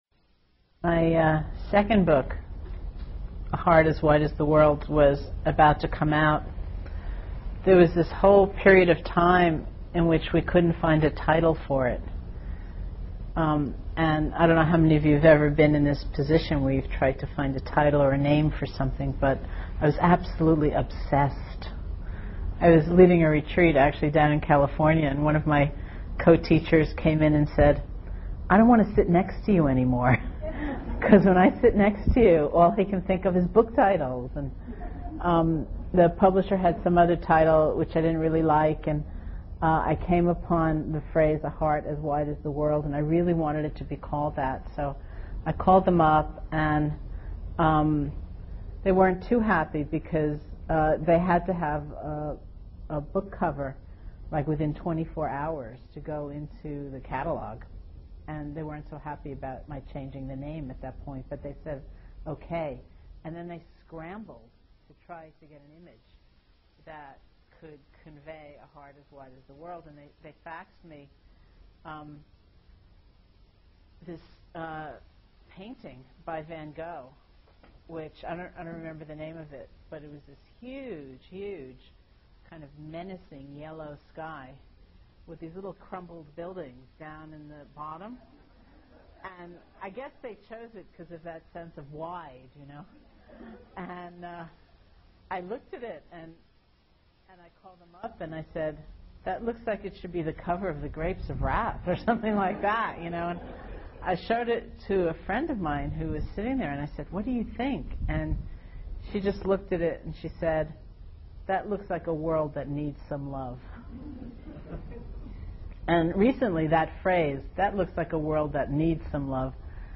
Teacher: Sharon Salzberg Date: 2003-07-13 Venue: Seattle Insight Meditation Center Series [display-posts] Description Metta Non-residential Weekend TalkID=784